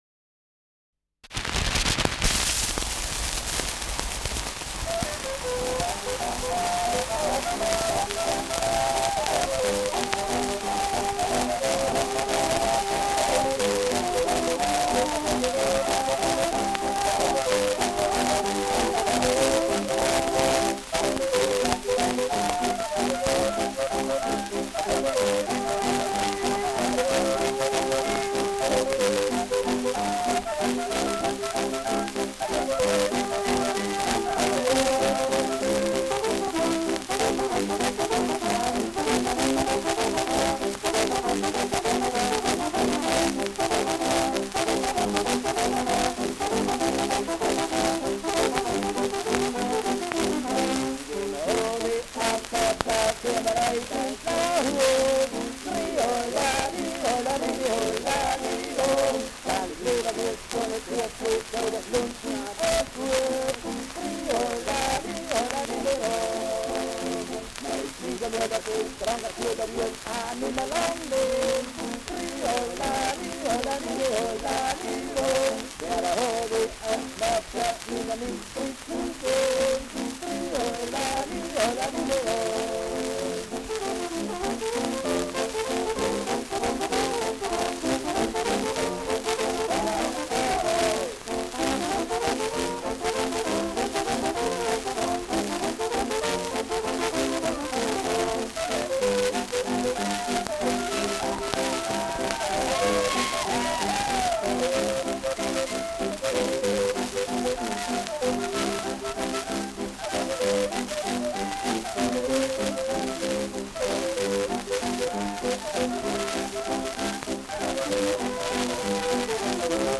Walzer mit Gesang
Schellackplatte